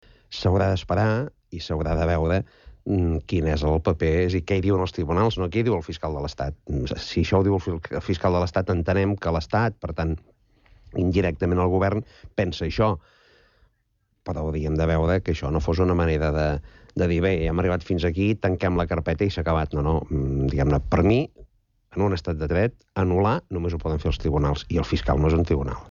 Però el vice-president del govern, Josep-Lluís Carod-Rovira, avisa que 'les sentències judicials, solament les pot declarar nul·les un tribunal, no pas la fiscalia' (